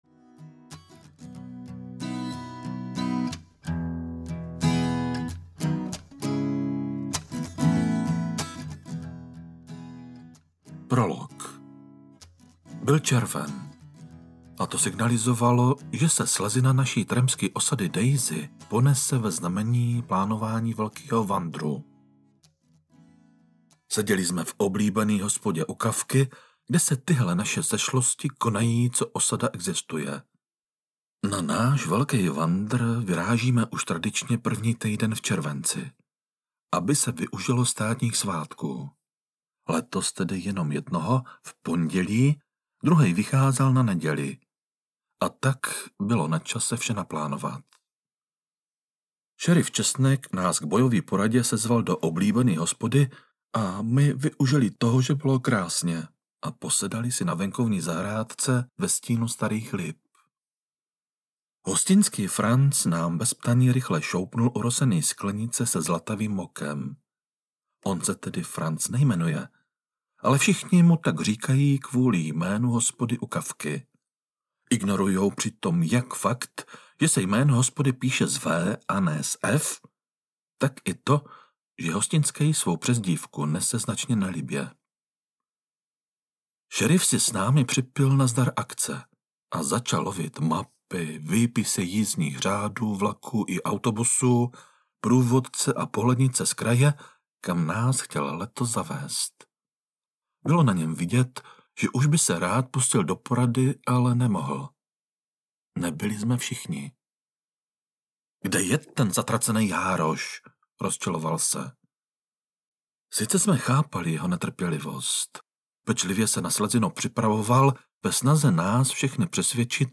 Případ pohřešovaného háčka audiokniha
Ukázka z knihy